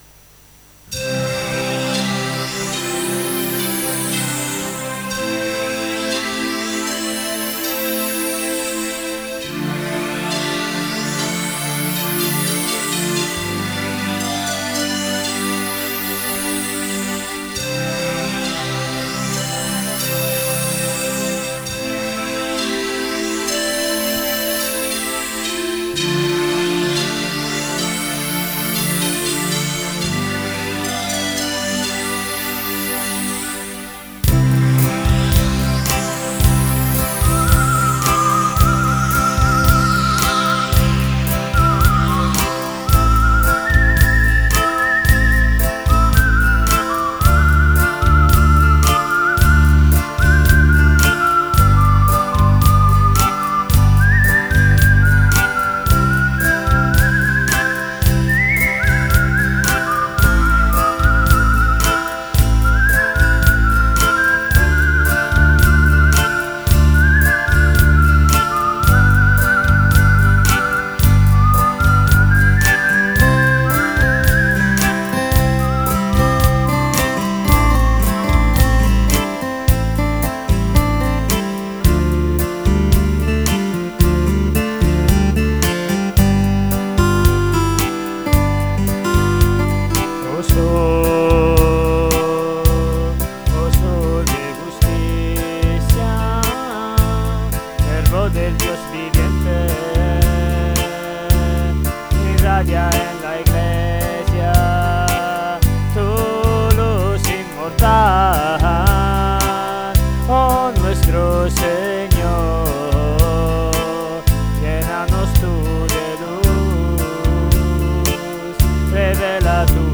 notas de guitarra